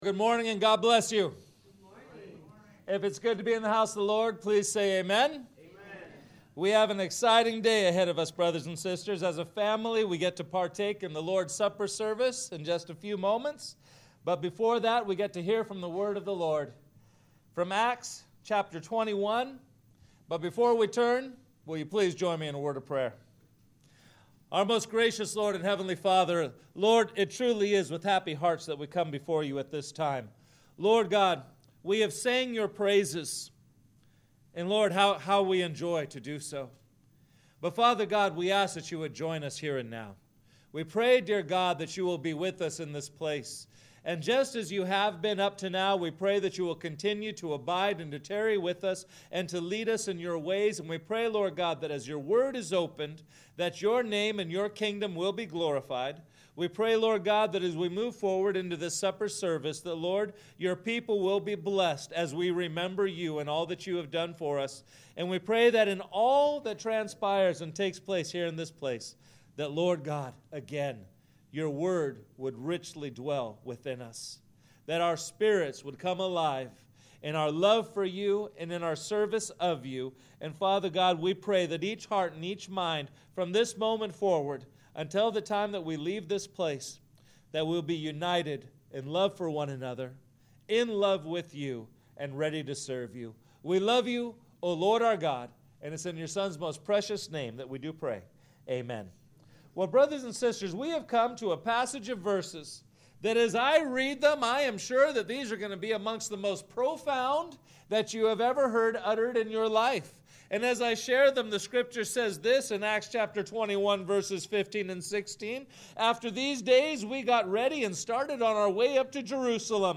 Sermons | First Baptist Church Solvang